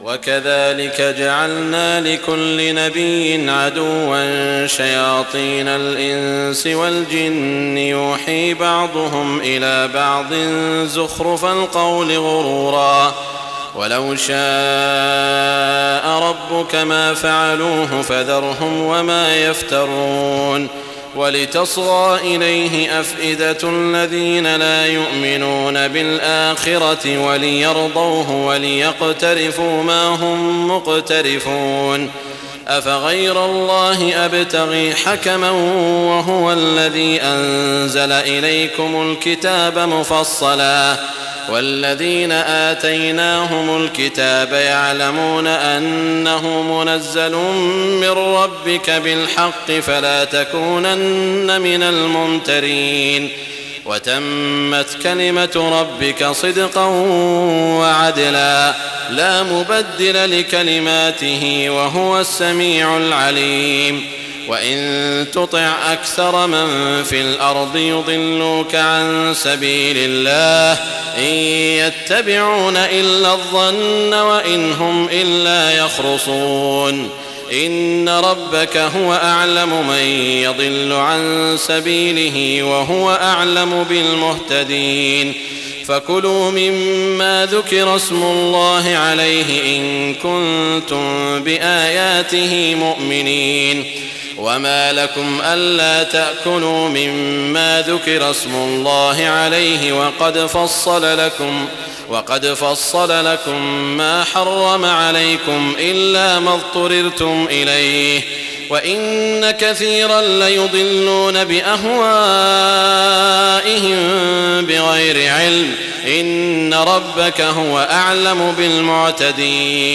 تهجد ليلة 28 رمضان 1419هـ من سورتي الأنعام (112-165) و الأعراف (1-30) Tahajjud 28 st night Ramadan 1419H from Surah Al-An’aam and Al-A’raf > تراويح الحرم المكي عام 1419 🕋 > التراويح - تلاوات الحرمين